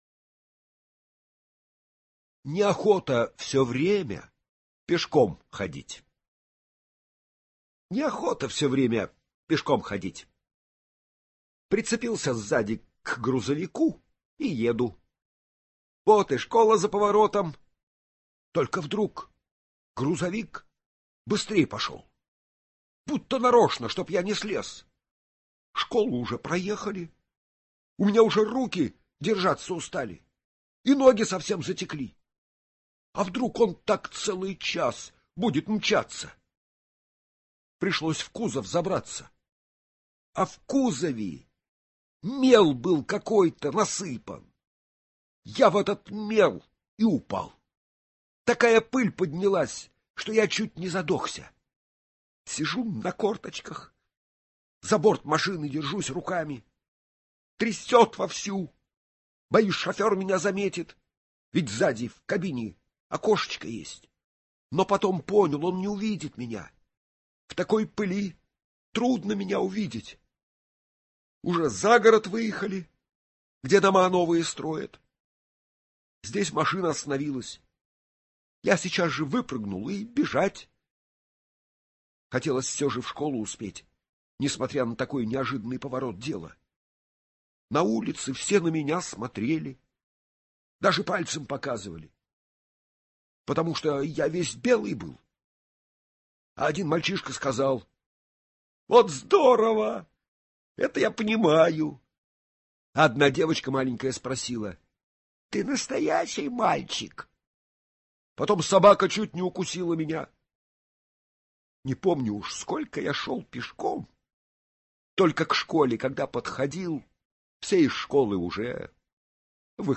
Аудиорассказ «Неохота все время пешком ходить»